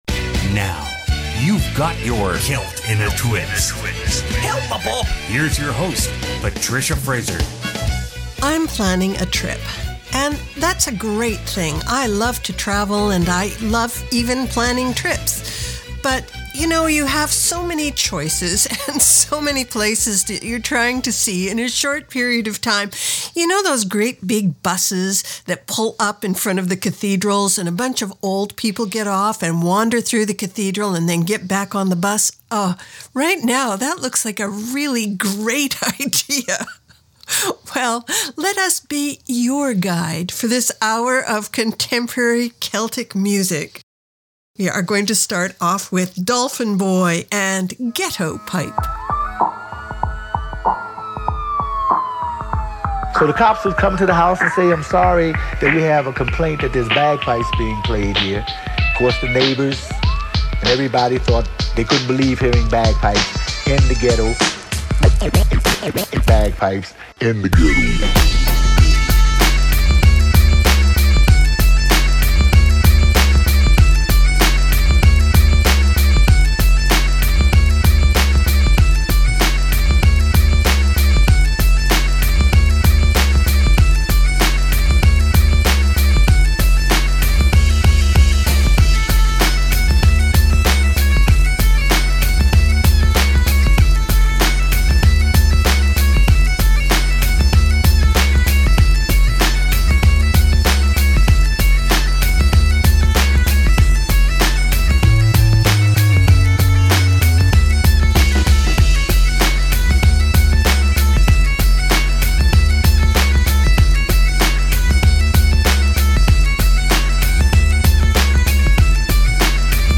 Canada's Contemporary Celtic Hour